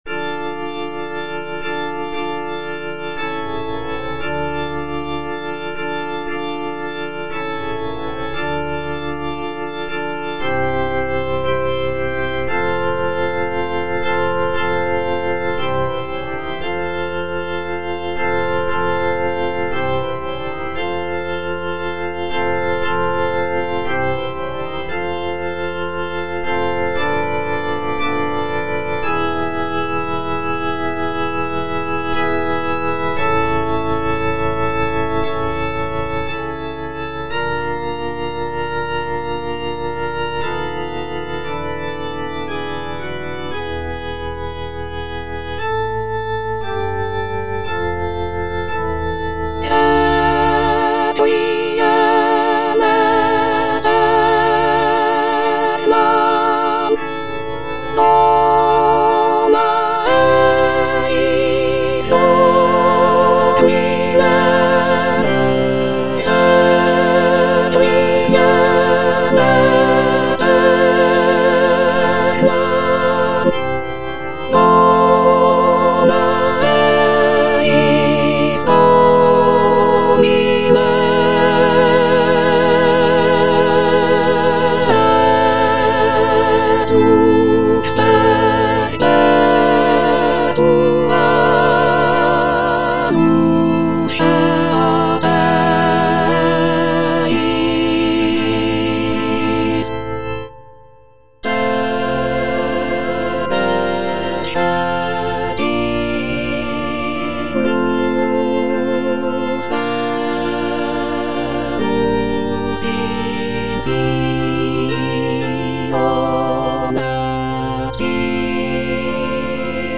F. VON SUPPÈ - MISSA PRO DEFUNCTIS/REQUIEM Introitus - Soprano (Emphasised voice and other voices) Ads stop: auto-stop